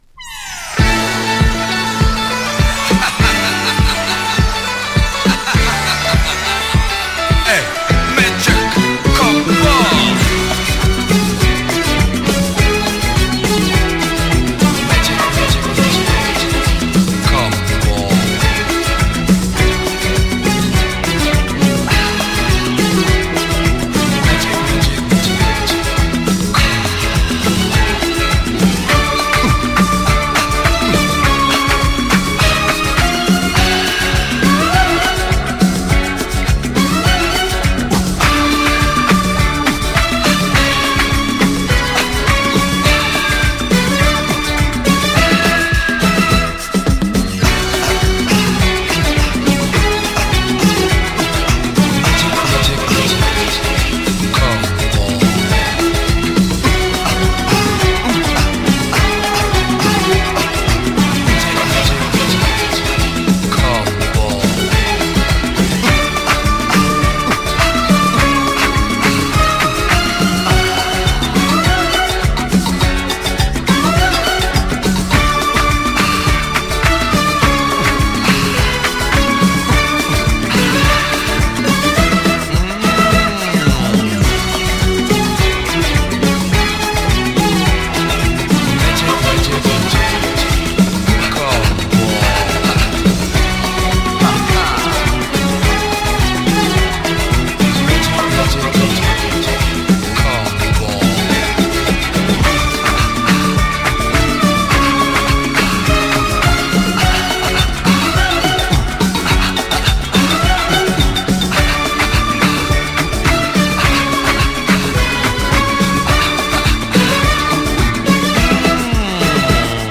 コズミックなシンセ、ハンドクラップを交えた緩やかなグルーヴが何とも心地良いディスコ・チューン。